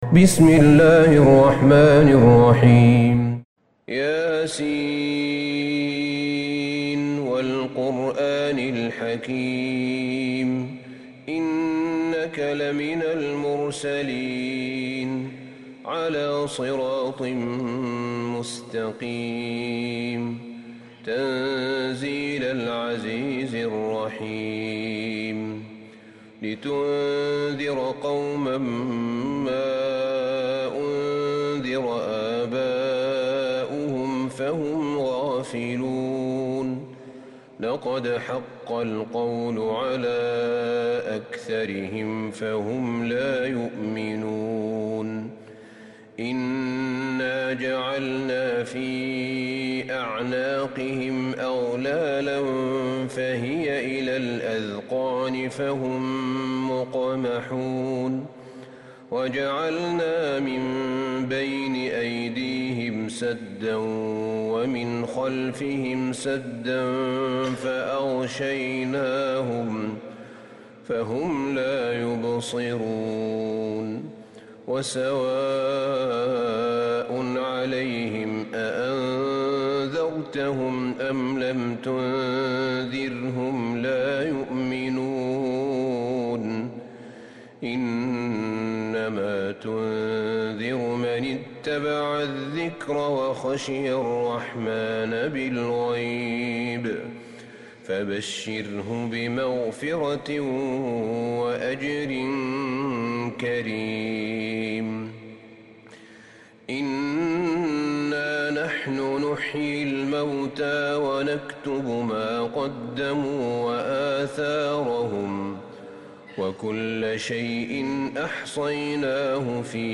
سورة يس Surat YaSin > مصحف الشيخ أحمد بن طالب بن حميد من الحرم النبوي > المصحف - تلاوات الحرمين